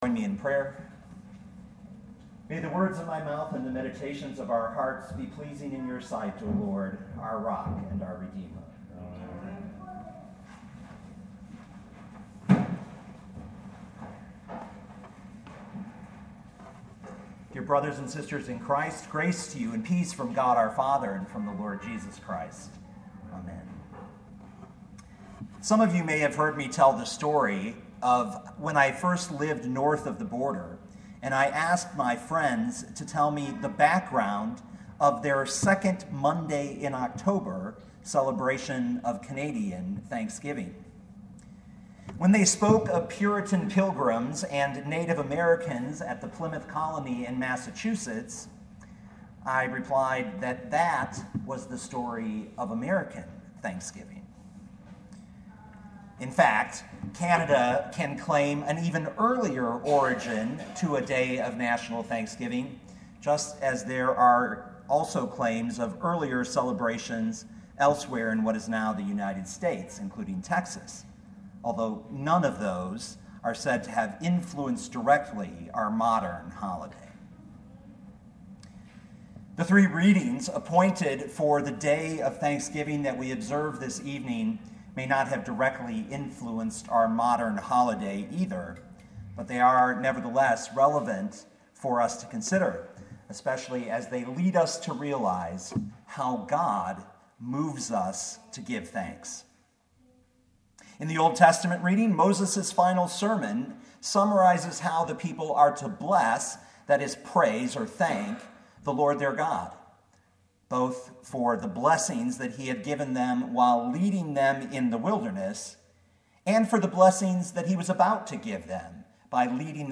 Luke 17:11-19 Listen to the sermon with the player below, or, download the audio.